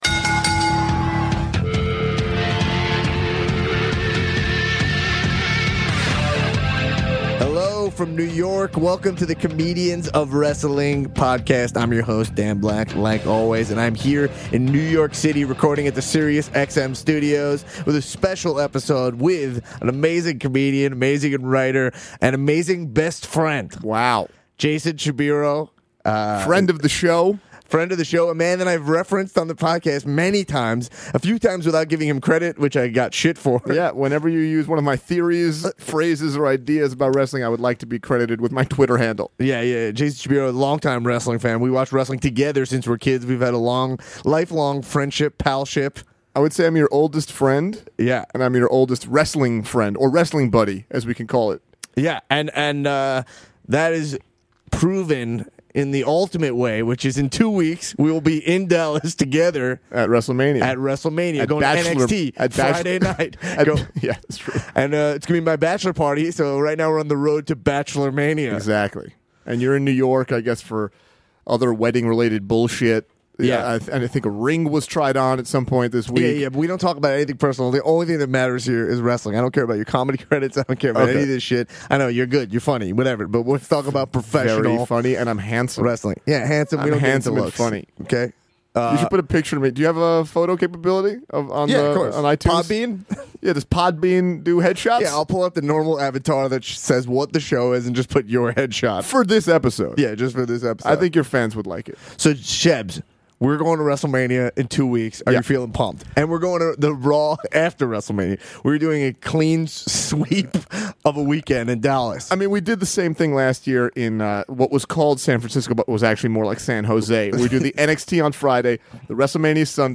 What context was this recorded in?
Special episode recorded in NY at Sirius XM!